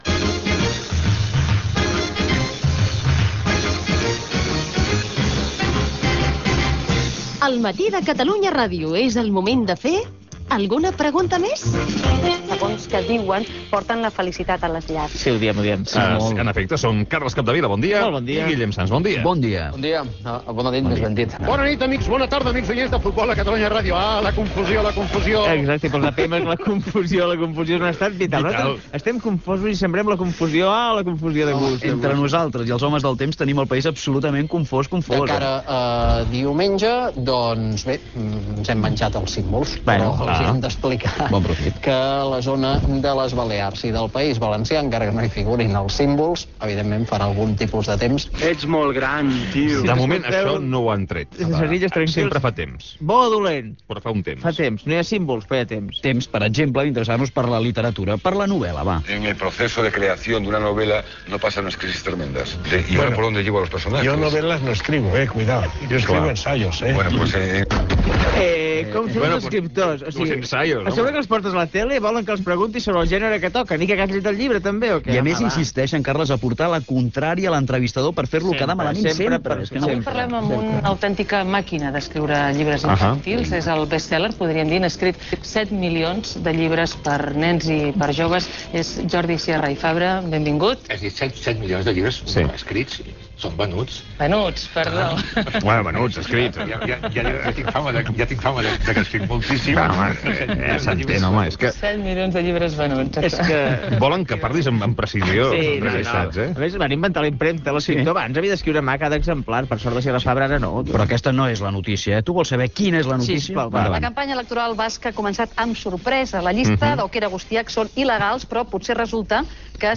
4869d32b036928a4d3b88b7b965cf79c14ec05e9.mp3 Títol Catalunya Ràdio Emissora Catalunya Ràdio Cadena Catalunya Ràdio Titularitat Pública nacional Nom programa El matí de Catalunya Ràdio Descripció Espai "Alguna pregunta més?" Careta de l'espai i fragments radiofòncis relacionats amb els escriptors, la mort del Sant Pare Joan Pau II, el partit de la lliga de futbol Real Madrid F.C:Barcelona, etc.
Info-entreteniment